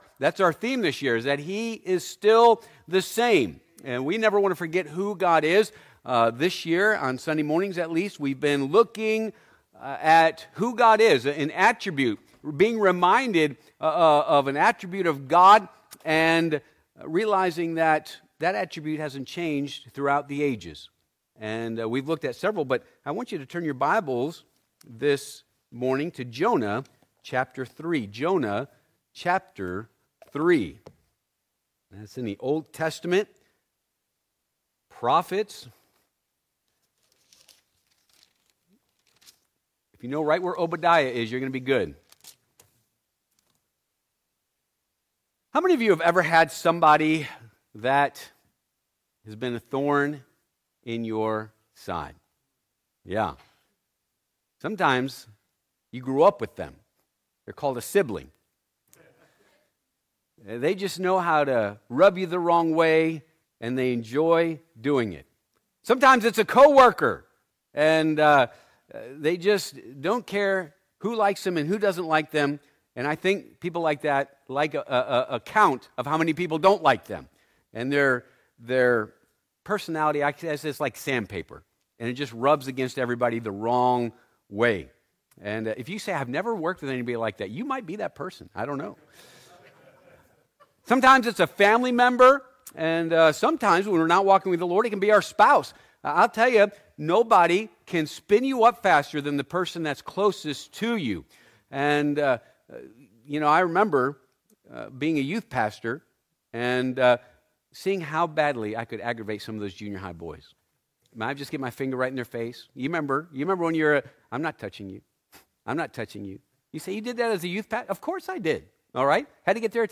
Passage: Jonah 3-4 Service Type: Sunday AM Topics